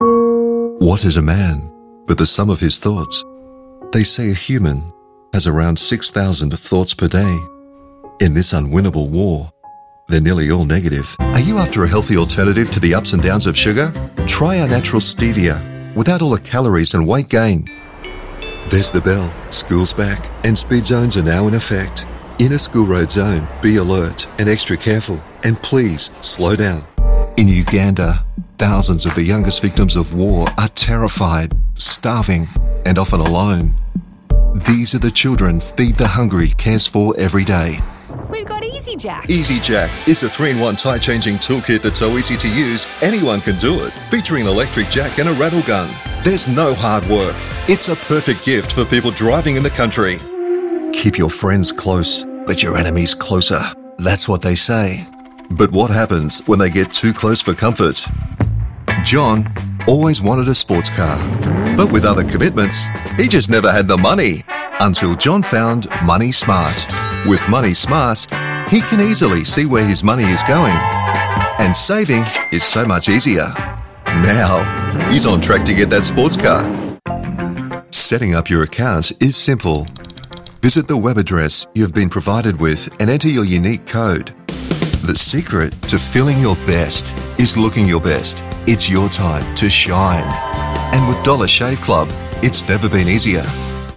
Professional voice actors behind Pfister Faucets ads, delivering polished, home-lifestyle reads.
A friendly or corporate, warm or deep, upbeat or commercial, sales, conversational, educational, documentary, narrator voiceover. A radio and TV great voice for ads or spots.